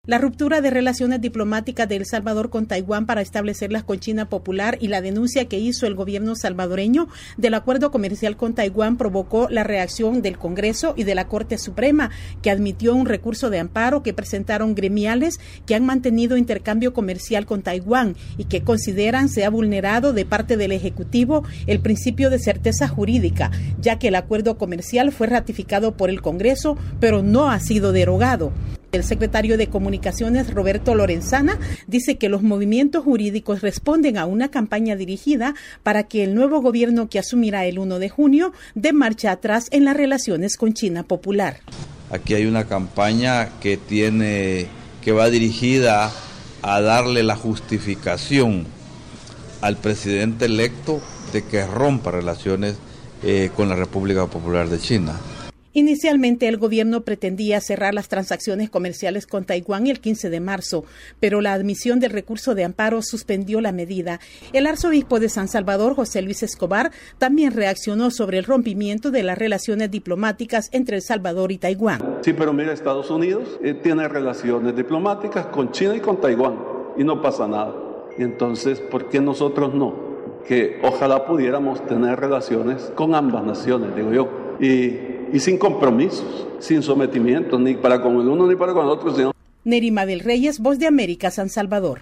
VOA: El Salvador Informe